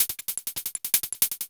Hats 02.wav